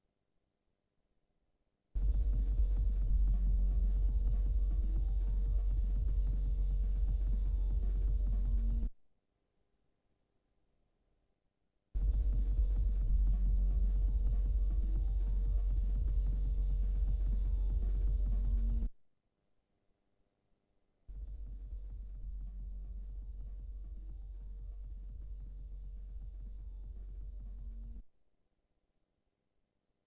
Having set this to a comfortable listening level the following link is a sequence of the three capacitor voltage signals.
The third is the 10u electrolytic, and is obviously at a lower level, confirming that this capacitor has the least effect on the signal. In the second file only one of the stereo channels is used.
CAPACITOR VOLTAGES IN SEQUENCE.